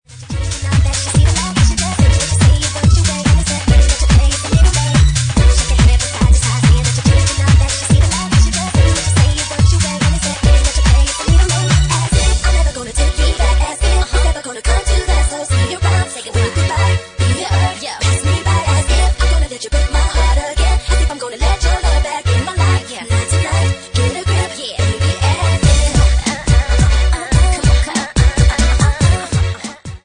Genre:Bassline House
Bassline House at 143 bpm